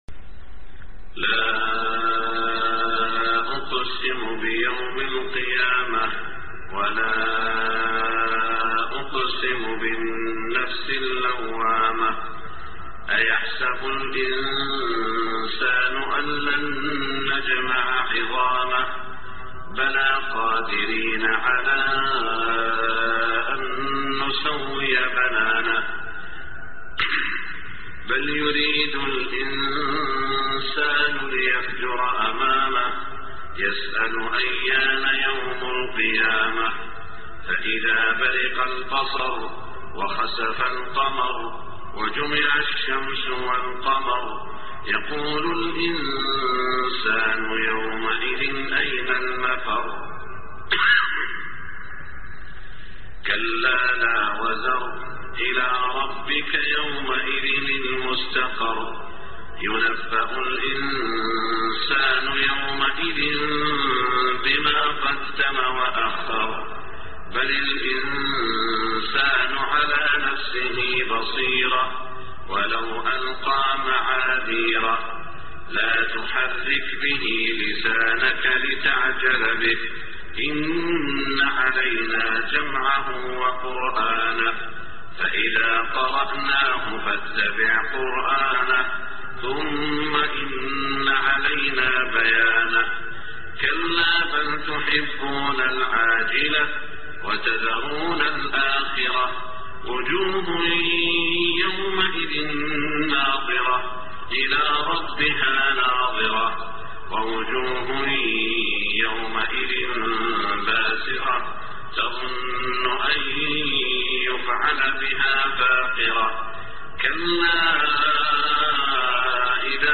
صلاة الفجر 6-2-1427 سورتي القيامة و البلد > 1427 🕋 > الفروض - تلاوات الحرمين